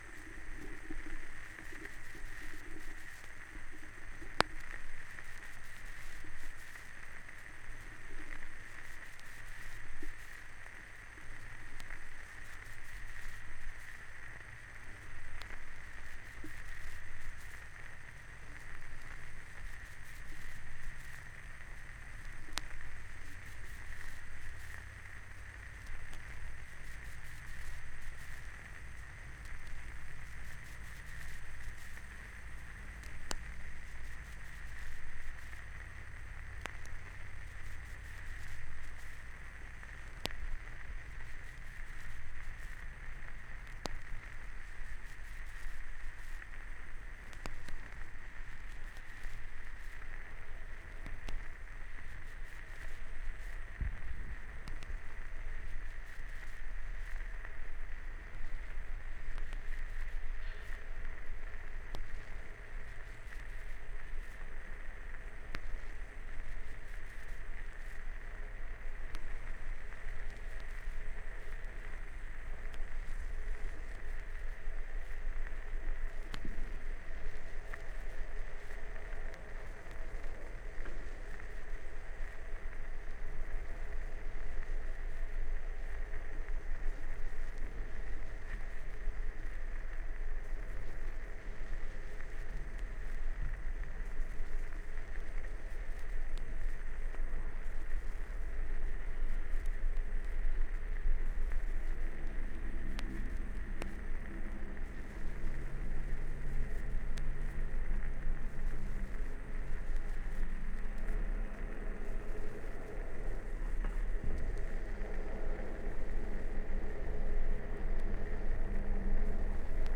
Vinyl digitalisiert mit:
vorwärts und rückwärts
1A1 01 bow on bow sextet, turn table (16 Kanal stereo) 17.03